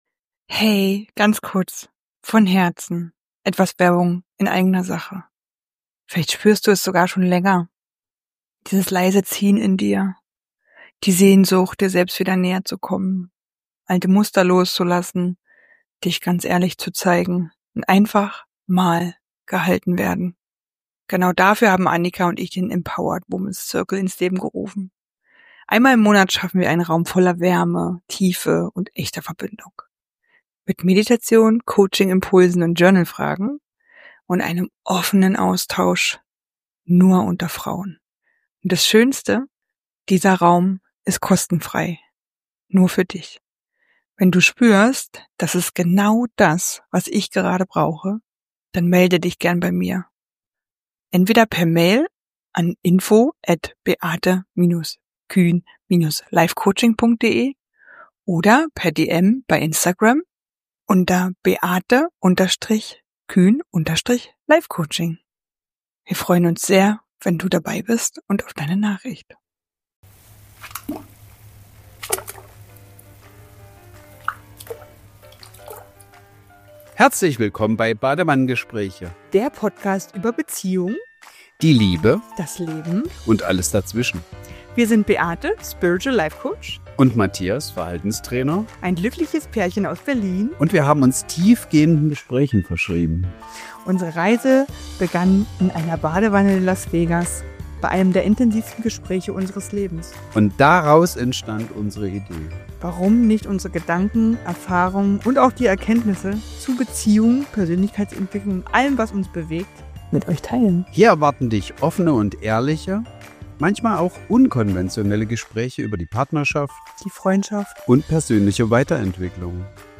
Eine Folge voller Leichtigkeit, kleinen Anekdoten und viel Lachen – perfekt für zwischendurch.